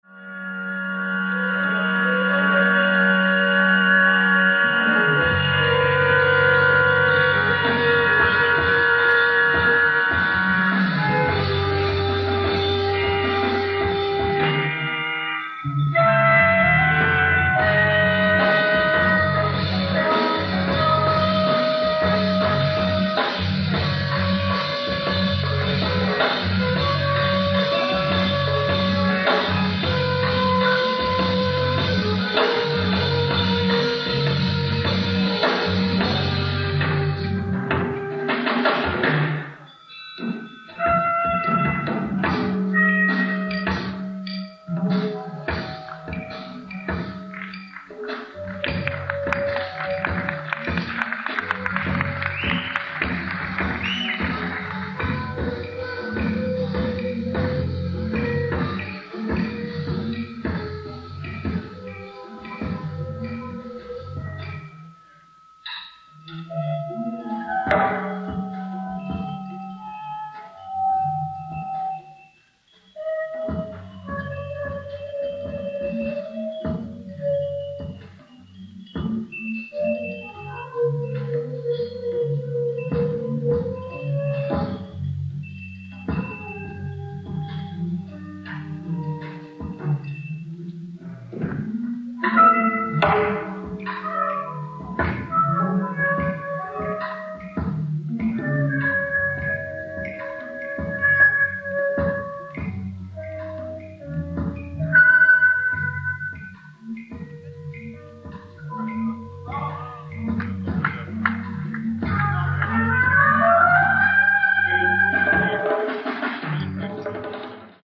ライブ・アット・ザ・プレイハウス、ホフストラ大学、ヘンプステッド、ニューヨーク 03/22/1975
海外マニアによるリマスター音源！！
※試聴用に実際より音質を落としています。